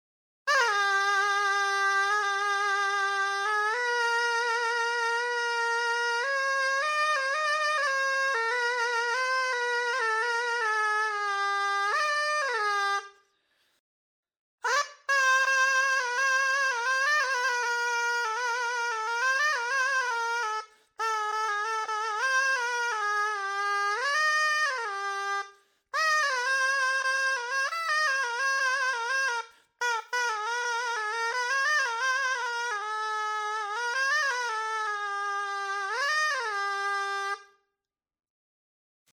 Reed
In ritualistic settings, its penetrating sound has been known to evoke specific states of consciousness, akin to the practices observed by the Hamadsha brotherhood in Morocco engaged in Sufi rituals.
Zurna.mp3